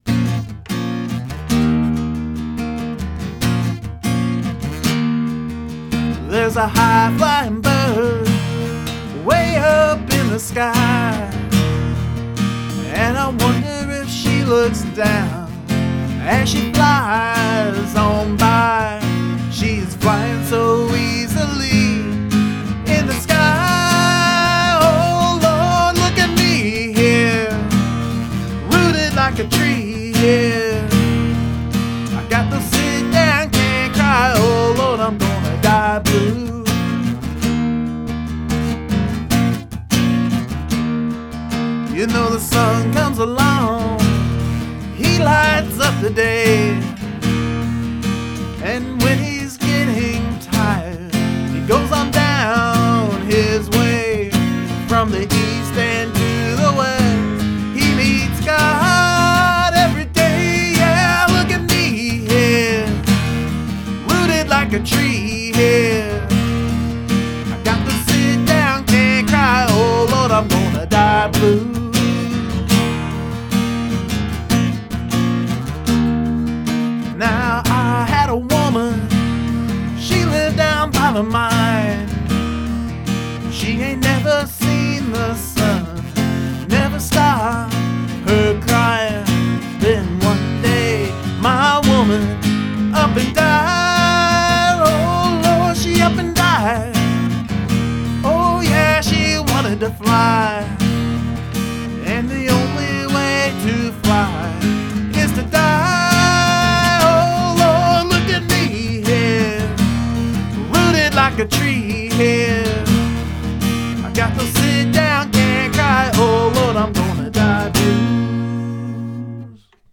yamaha acoustic/electric line in
vox on a MXL v67g microphone
That acoustic sounds great.
Lots of energy.
The acoustic line in track is as recorded.